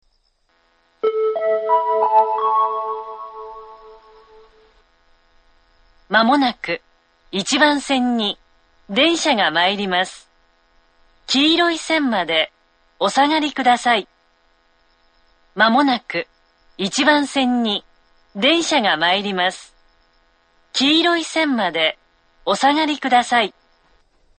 １番線接近放送
１番線発車メロディー 曲は「Cielo Estrellado」です。